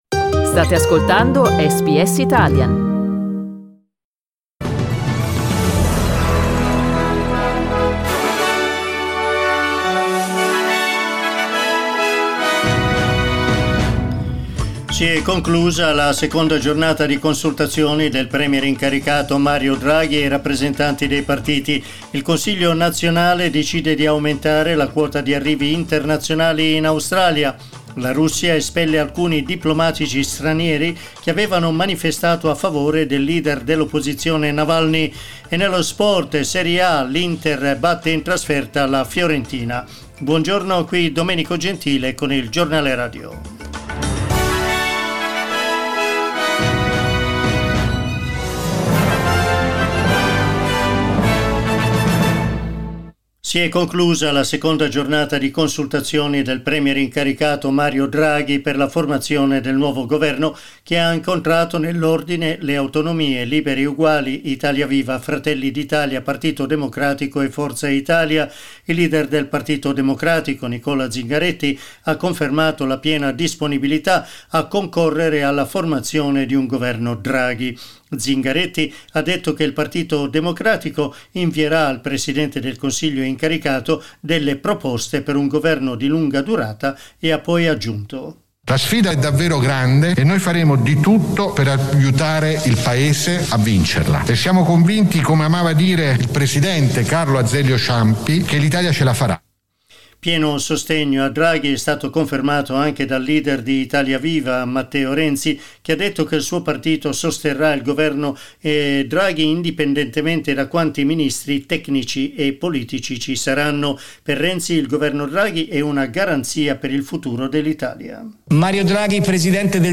Our news bulletin in Italian